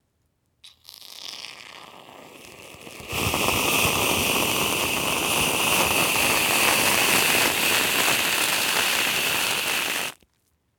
Sprühsahne ;)
:cool: Letzten Endes geht er mir nicht um die Sahne sondern um das Sprühgeräusch, was dabei entsteht :D Ein "crazy" Wunsch für nen...